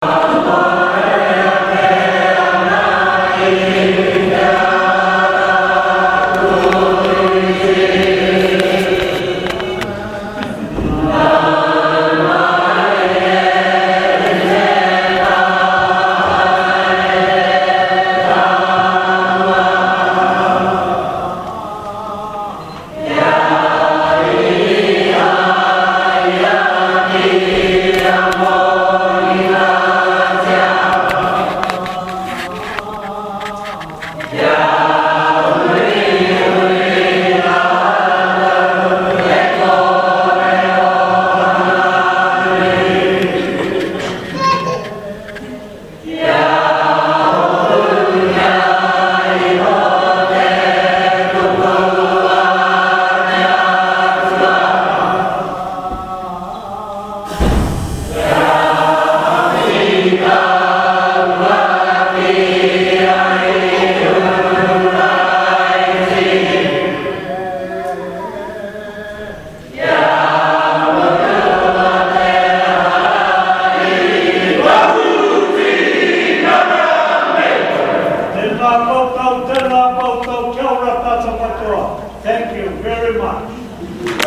Te Arawa singing #2